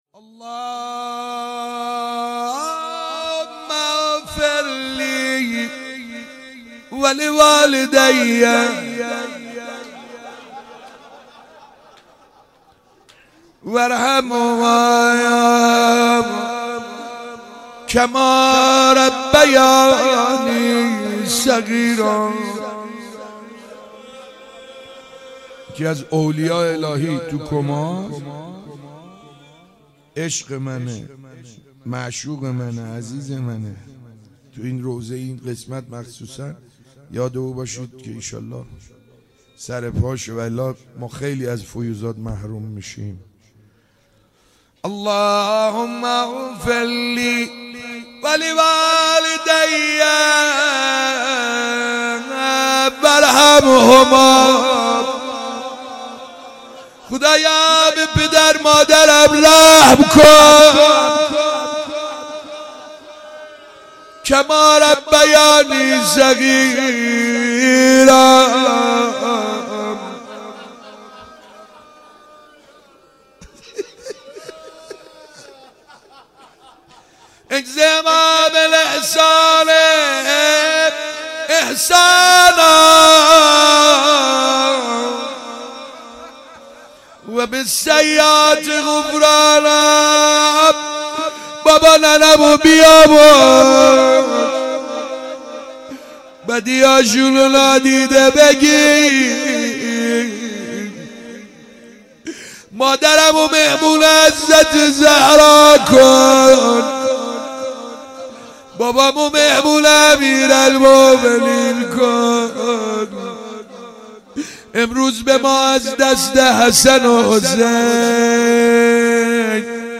2 دی 95_روضه_بخش دوم